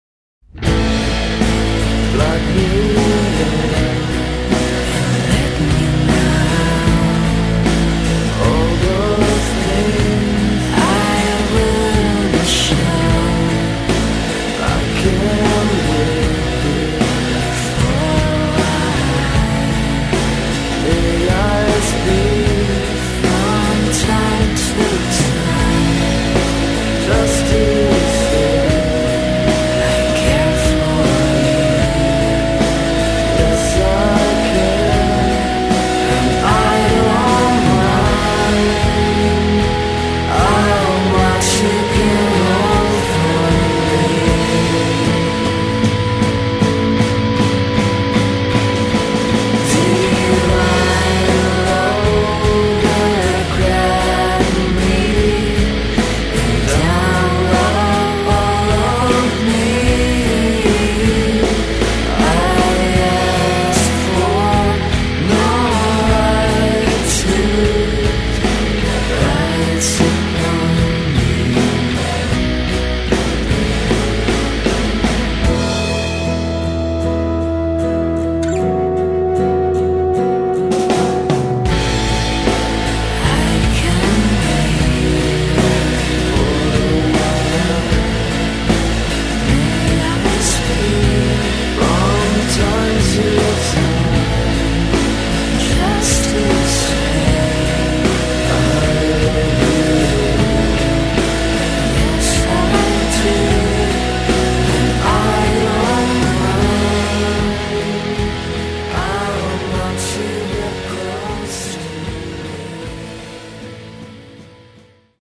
power-pop
con in evidenza la voce piena di grazia